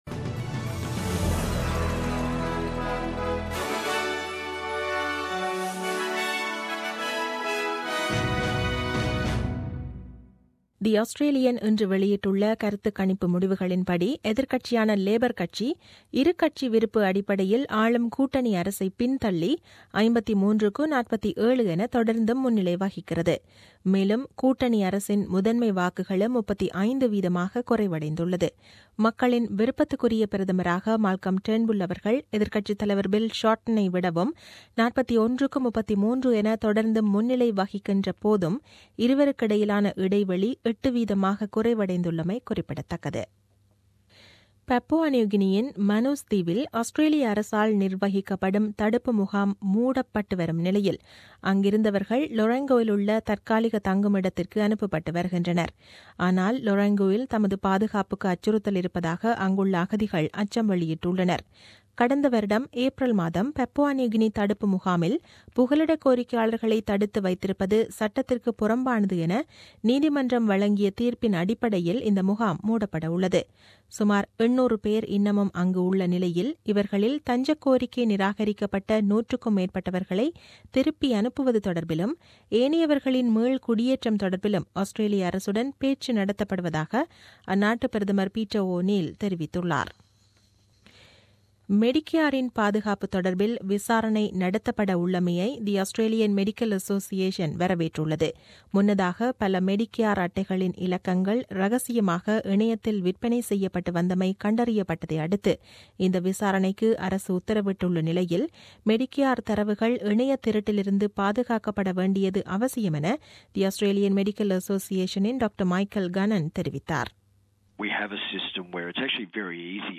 The news bulletin aired on 10 July 2017 at 8pm.